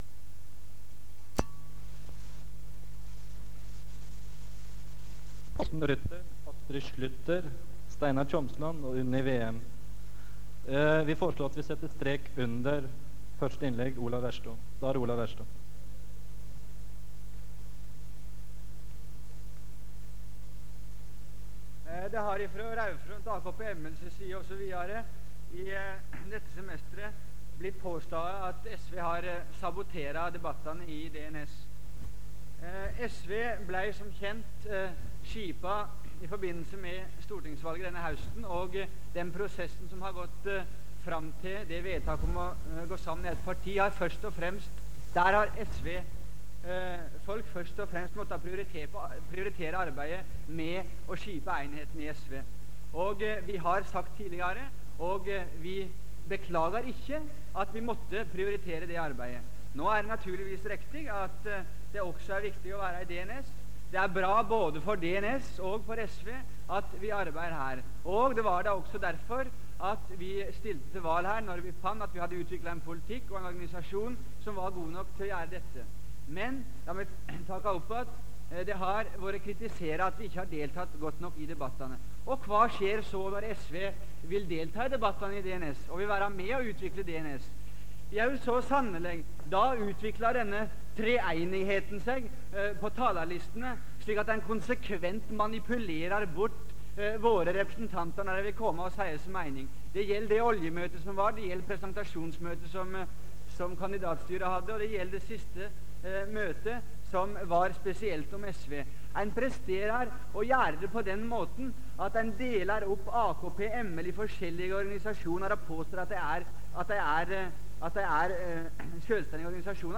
Det Norske Studentersamfund, Generalforsamling, 11.05.1974
Lydopptak